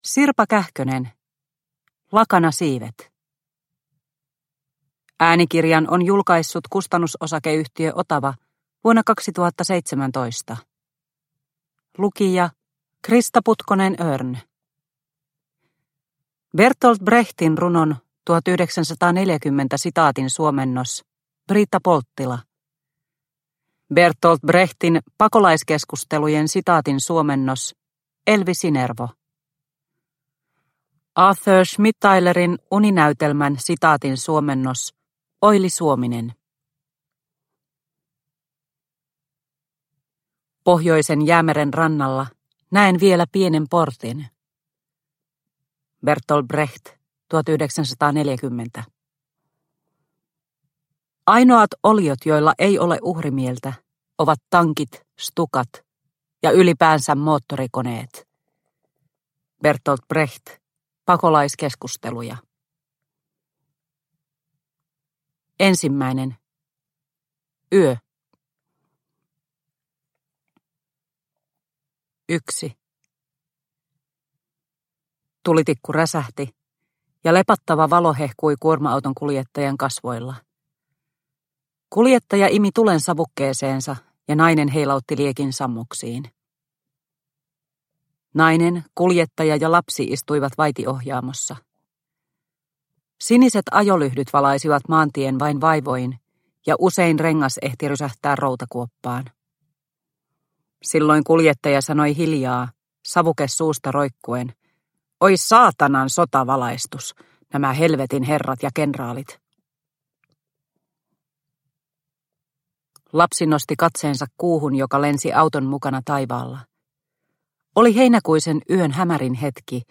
Lakanasiivet – Ljudbok – Laddas ner